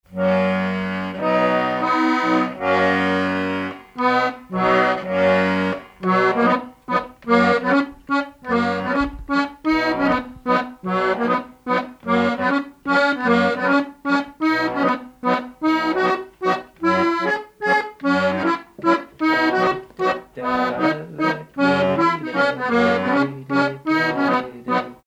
Mazurka
danse : mazurka
circonstance : bal, dancerie
Pièce musicale inédite